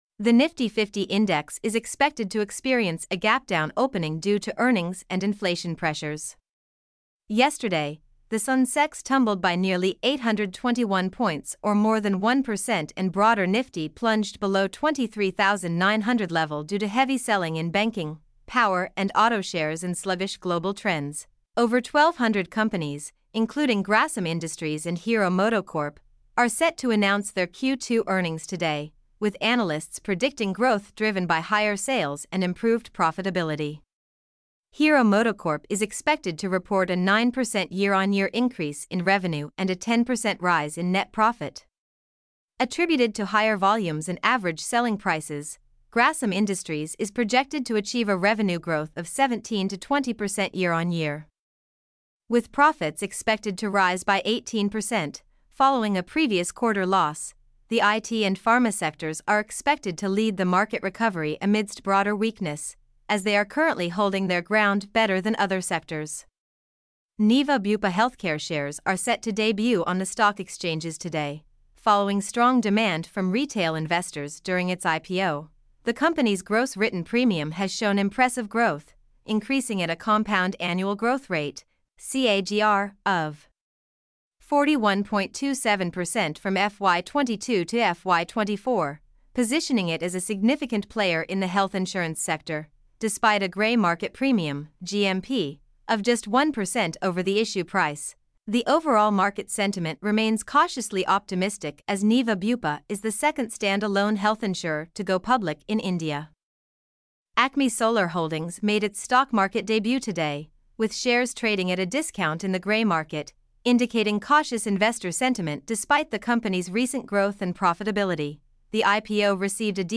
mp3-output-ttsfreedotcom-29.mp3